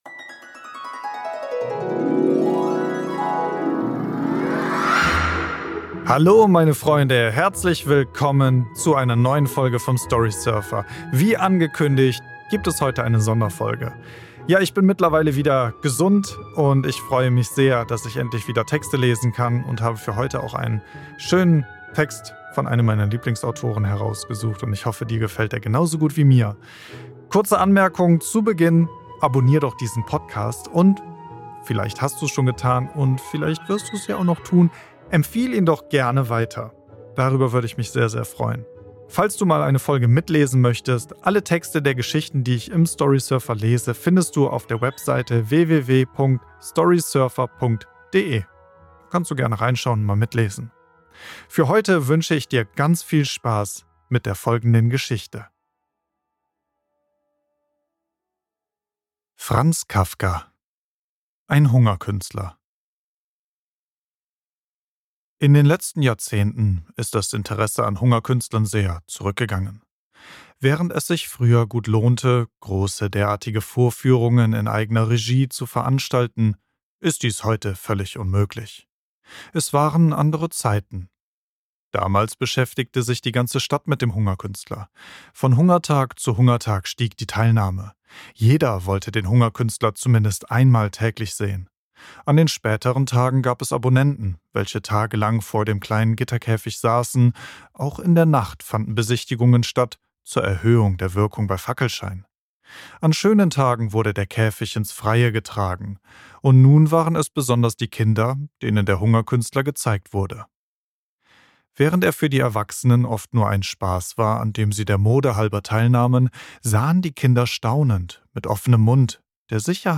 Franz Kafkas Geschichte “Ein Hungerkünstler” lese ich in dieser Folge des Storysurfer Podcasts.